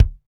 Index of /90_sSampleCDs/Northstar - Drumscapes Roland/KIK_Kicks/KIK_P_C Kicks x